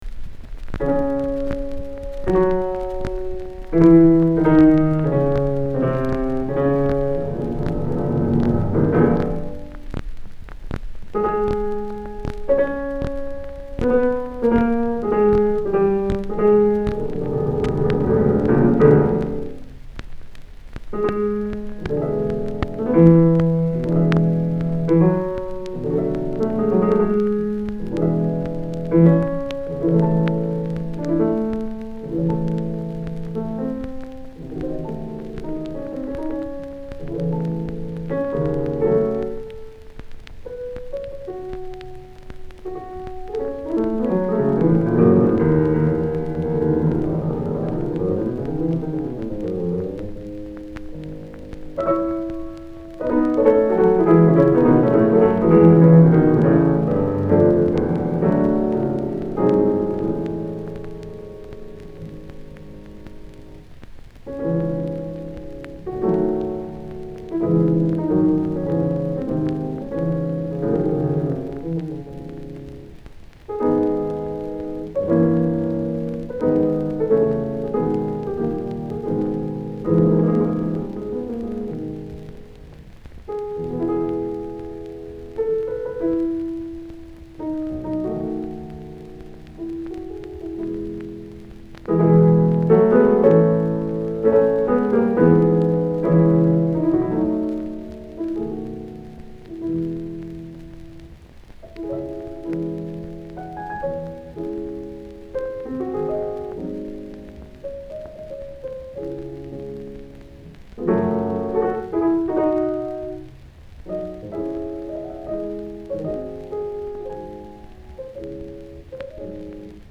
Vintage DIY audio articles & audiofile shellac and vinyl remastering
fortepiano solo